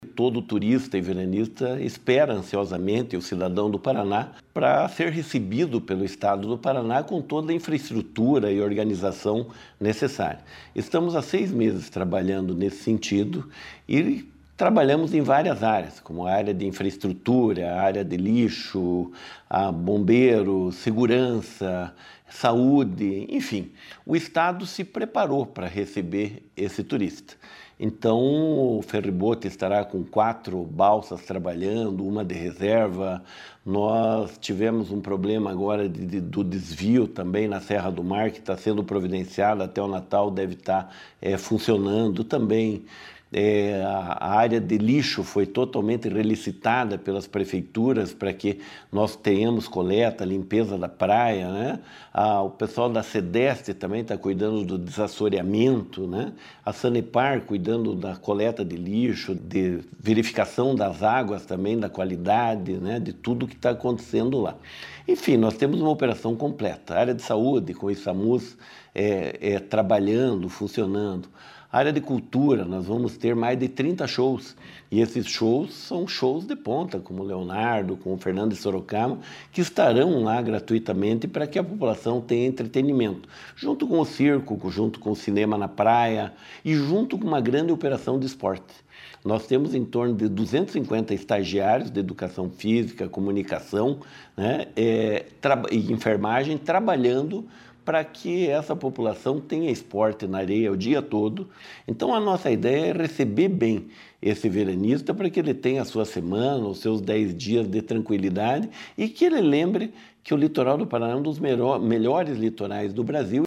Sonora do superintendente da Secretaria da Educação e Esporte, Hélio Wirbiski, sobre o Verão Maior Paraná